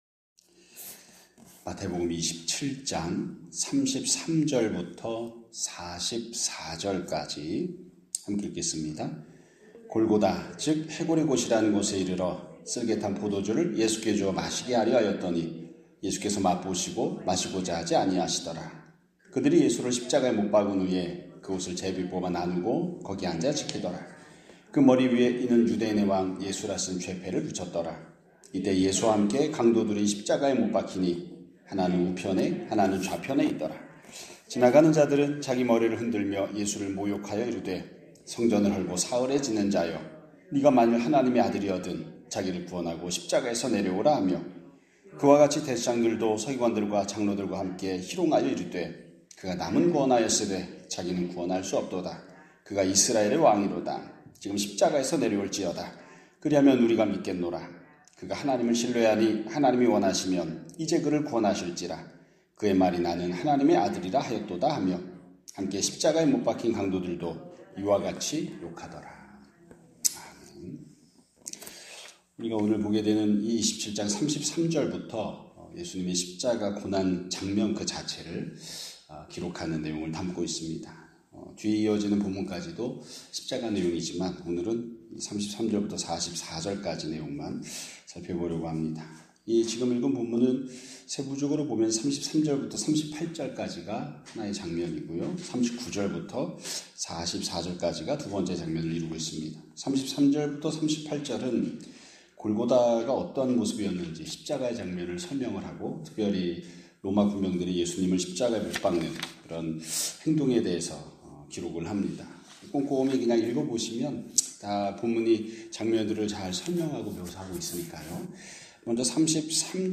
2026년 4월 21일 (화요일) <아침예배> 설교입니다.